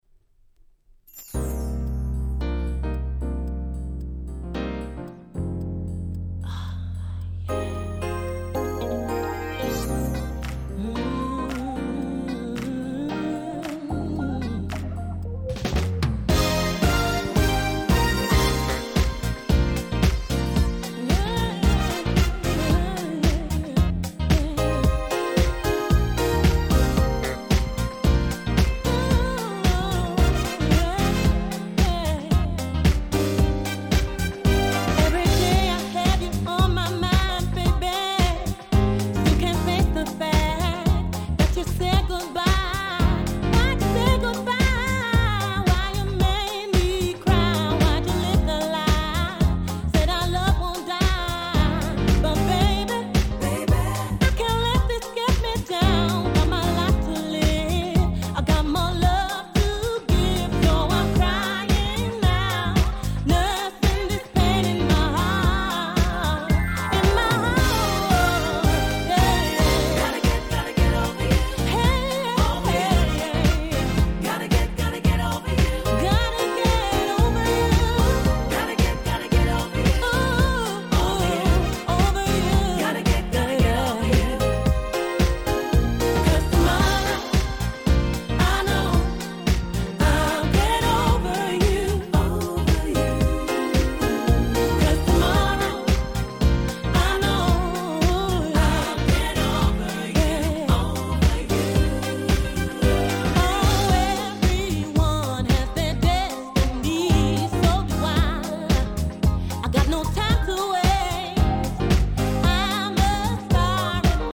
02' Nice Cover R&B !!